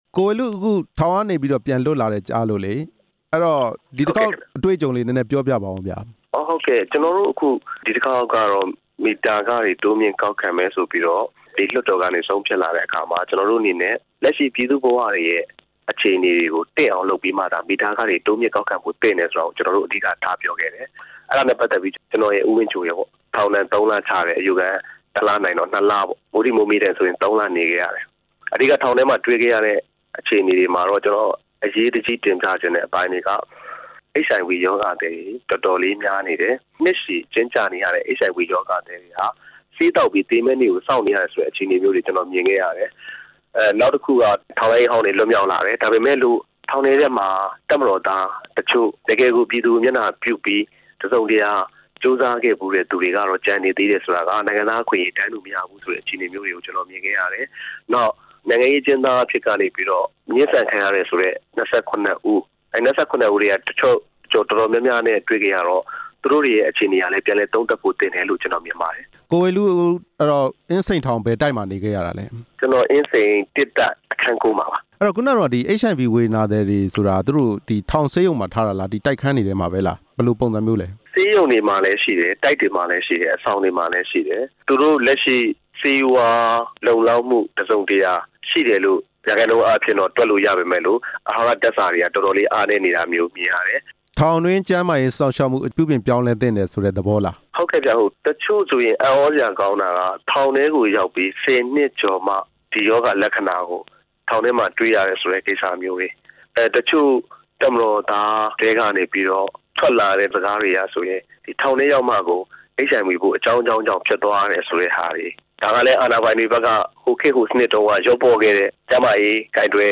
အင်းစိန်ထောင်ထဲက အခြေအနေတွေနဲ့ပတ်သက်လို့ မေးမြန်းချက်